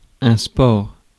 Ääntäminen
France (Paris): IPA: [œ̃ spɔʁ]